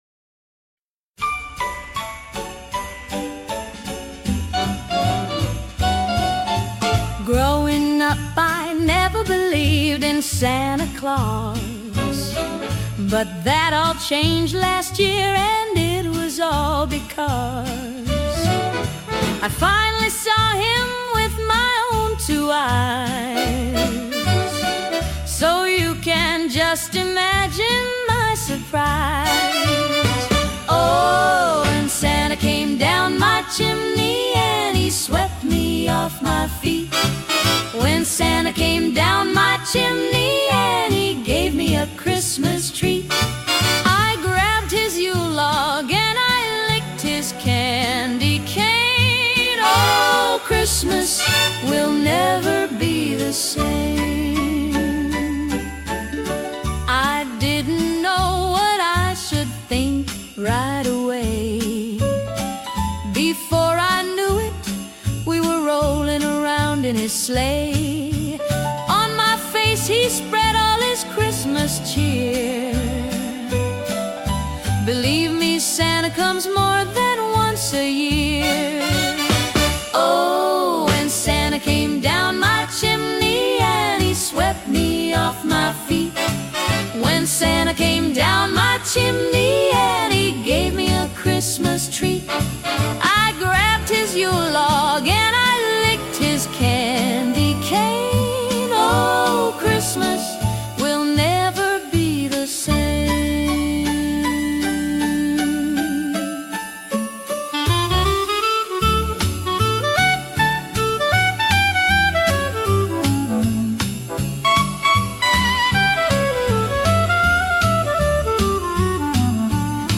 Santa Came Down My Chimney (50s)